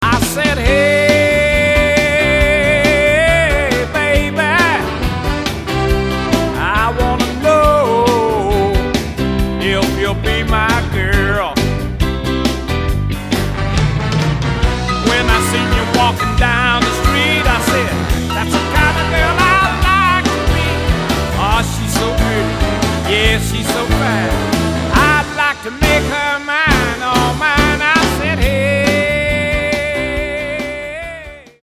The Best in Soul, Rhythm & Blues, and Carolina Beach Music